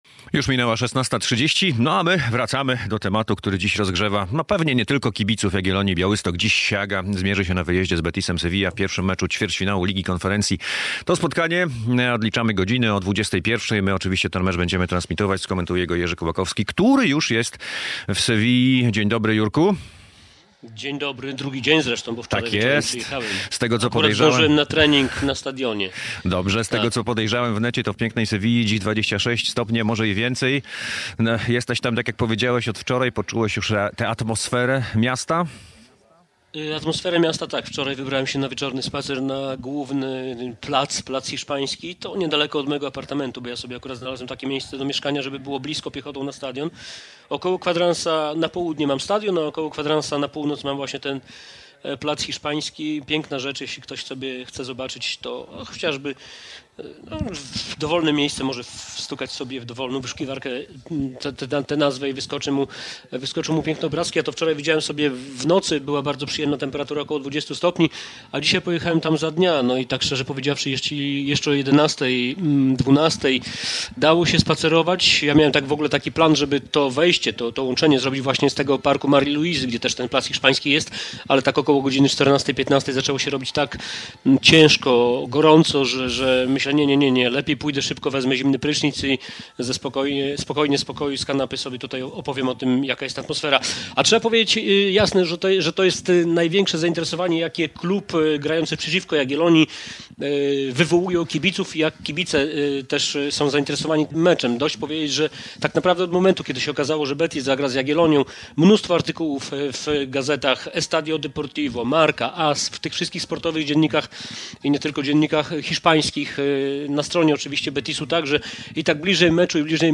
Atmosfera przed meczem Real Betis Sevilla - Jagiellonia Białystok - relacja